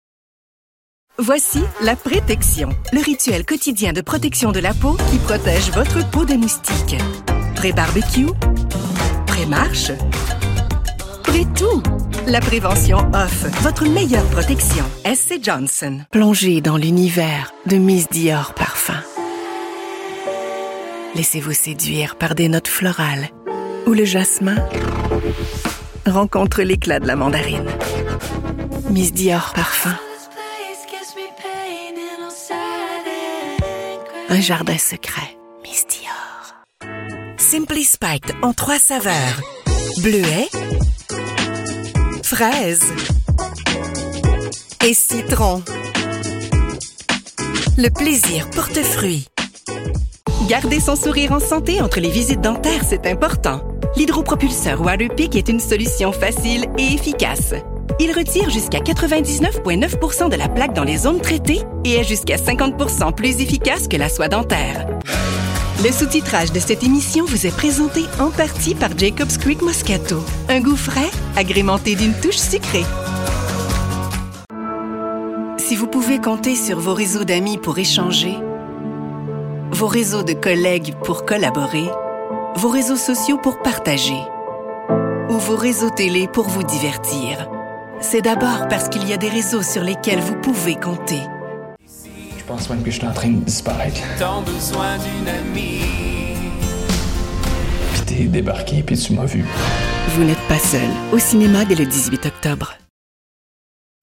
Demo comercial
Locutora francocanadiense profesional, nativa de Quebec, residente en Montreal.
-acento natural de Quebec
Servicio 5 estrellas | Audio de alta calidad estándar | Amplio rango vocal | Fácil de dirigir | Creación de melodías habladas